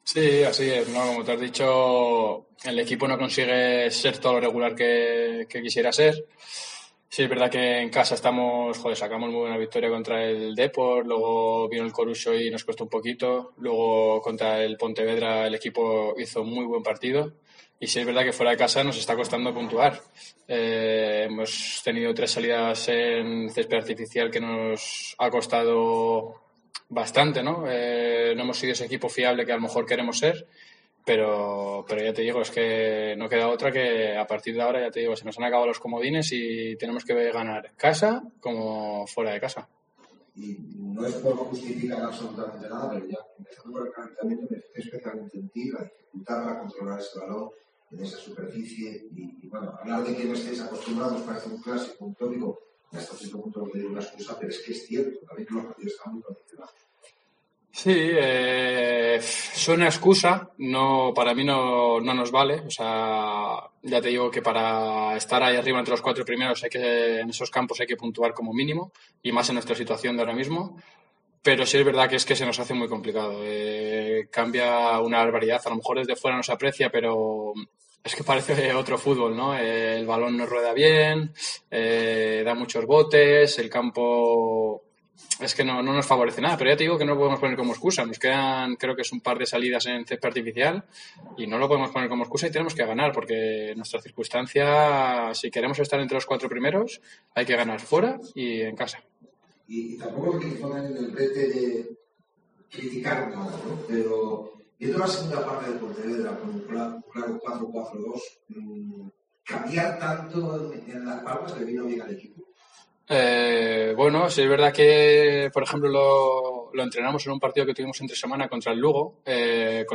Escucha aquí las declaraciones del centrocampista